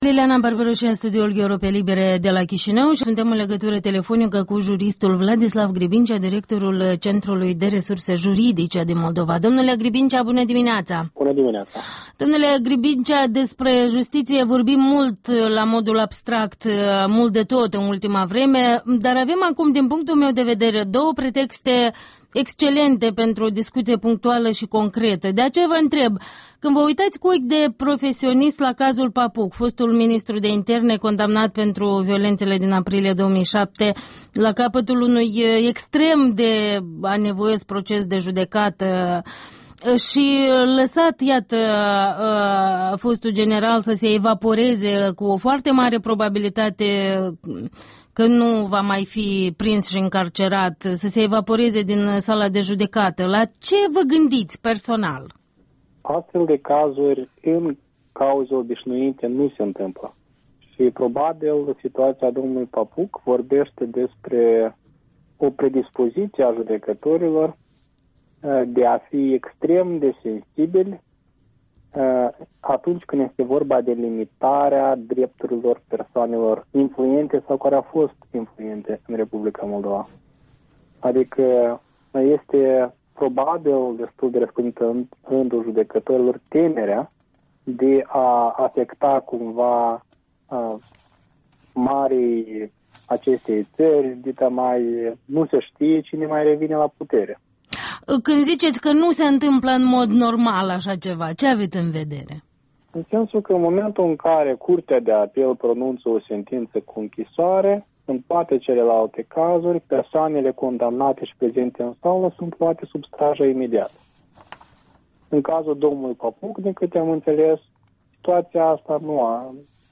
Interviul dimineții